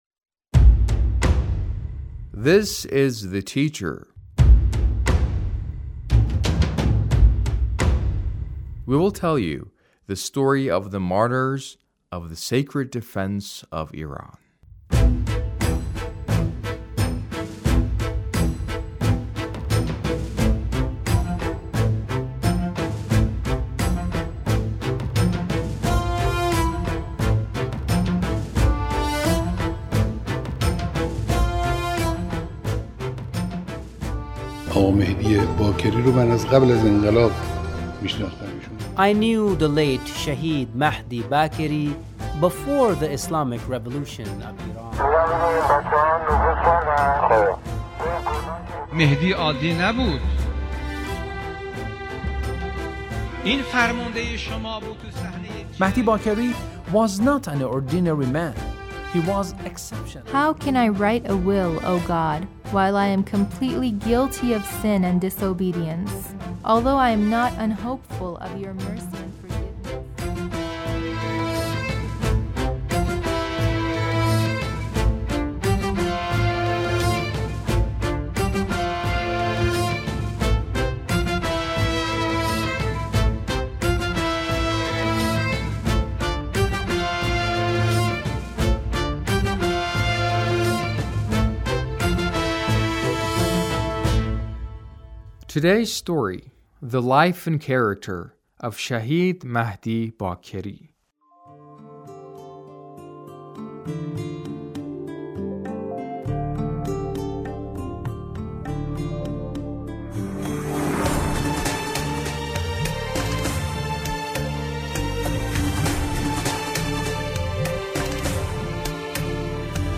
A radio documentary on the life of Shahid Mahdi Bakeri- Part 2